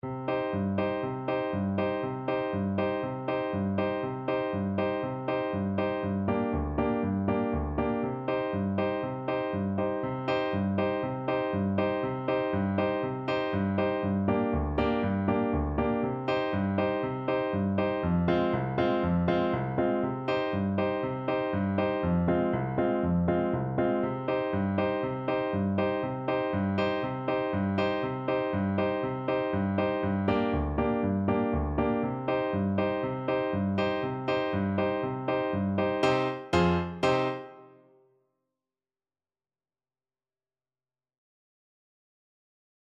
Allegro vivo (View more music marked Allegro)
4/4 (View more 4/4 Music)
Trombone  (View more Easy Trombone Music)
World (View more World Trombone Music)